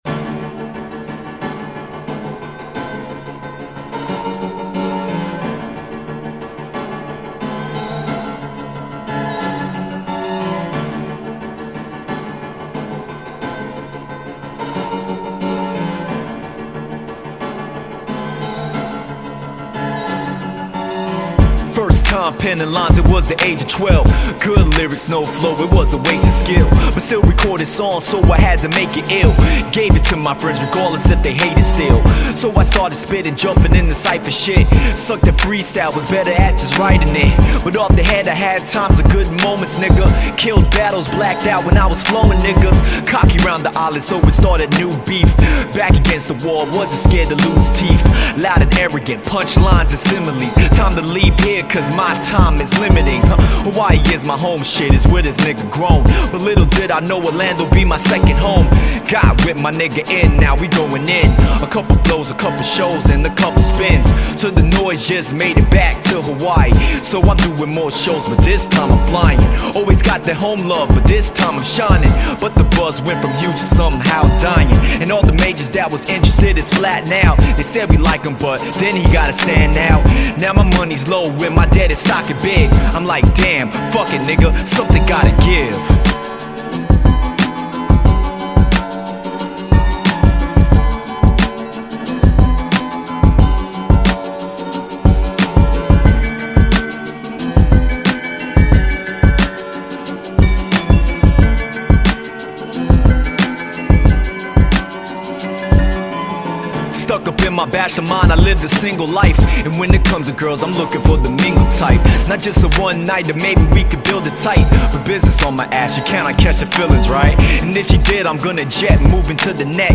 the dopest lyrics mixed with stellar production